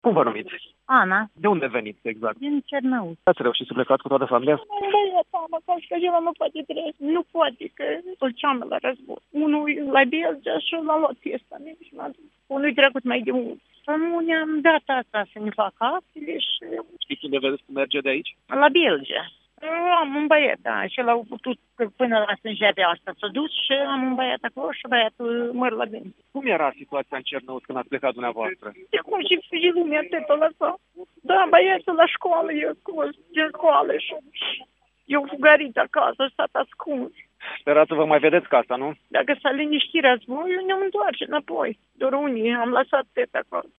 Este cazul unei femei din Cernăuți.